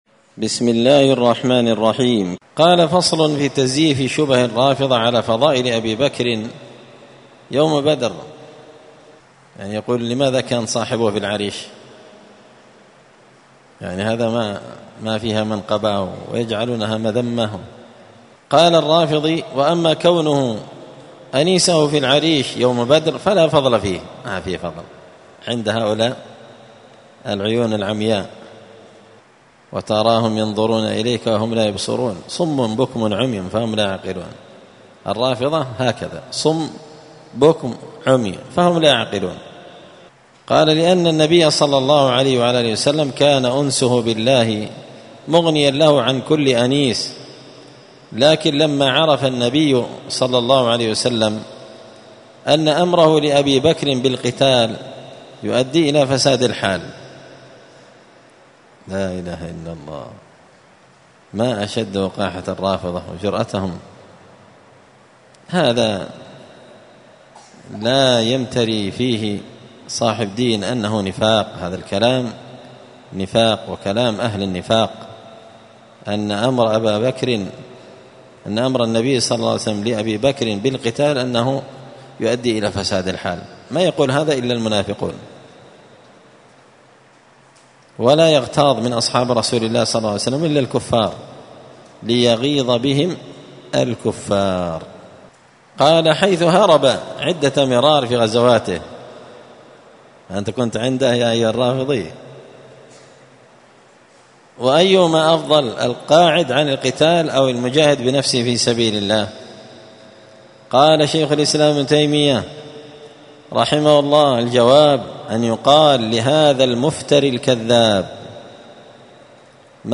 *الدرس التاسع والأربعون بعد المائتين (249) فصل في تزييف شبه الرافضة على فضائل أبي بكر يوم بدر*